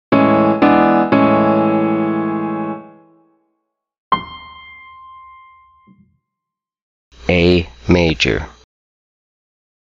This contextual based ear training method teaches note recognition within a key center using the sound of a piano.
10_A_major7_KN-C4_Piano_L2.mp3